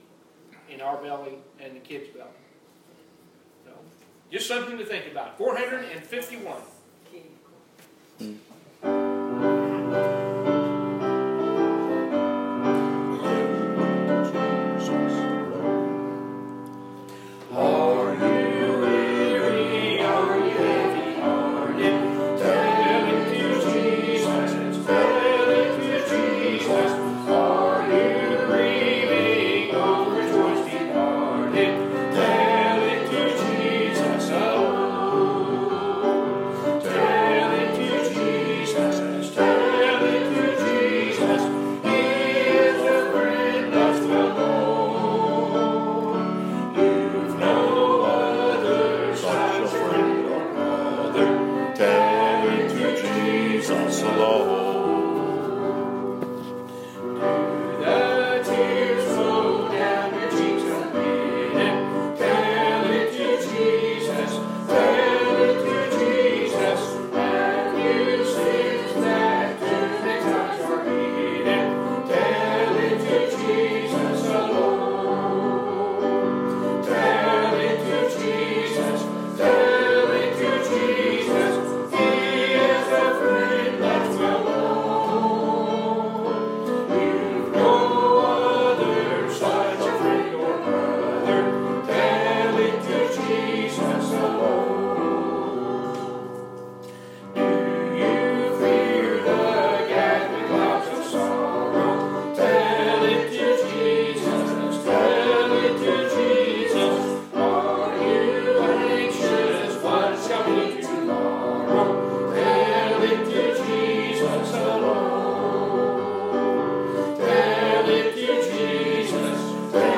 Purpose of this sermon: To lead to Christ enduring disciples that will not fold at the slightest or strongest storm.